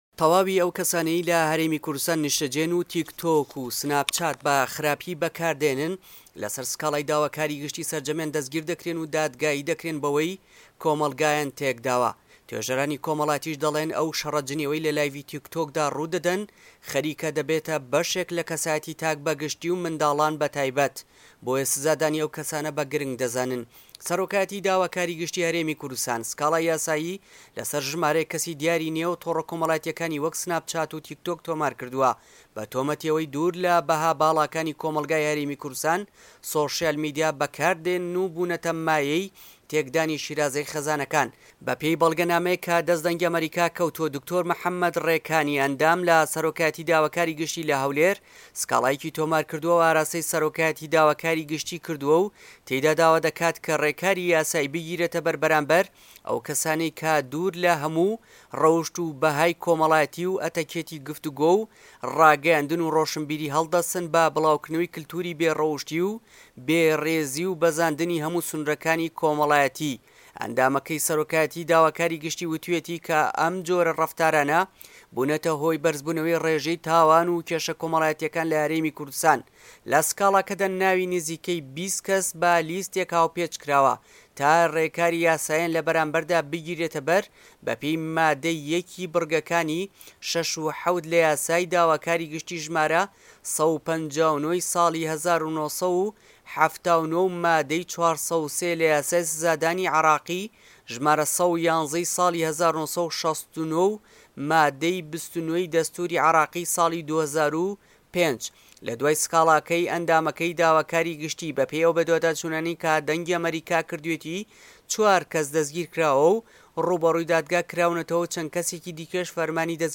راپۆرتی